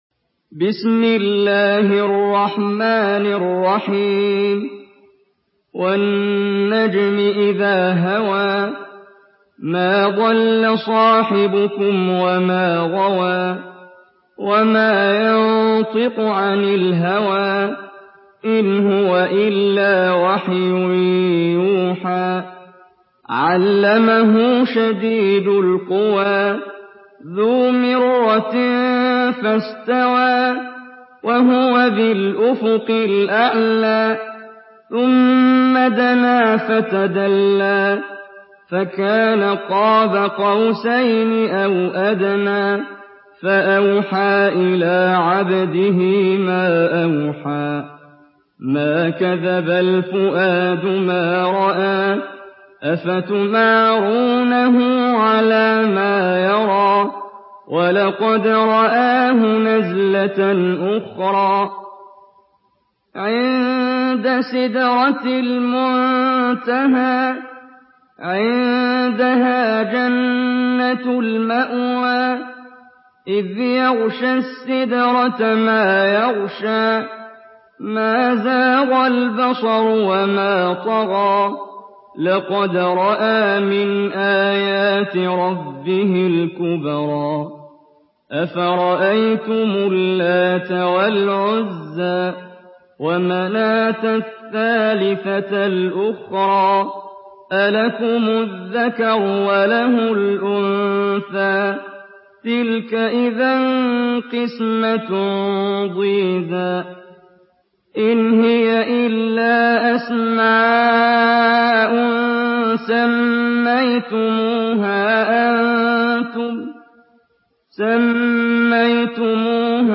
Surah النجم MP3 by محمد جبريل in حفص عن عاصم narration.
مرتل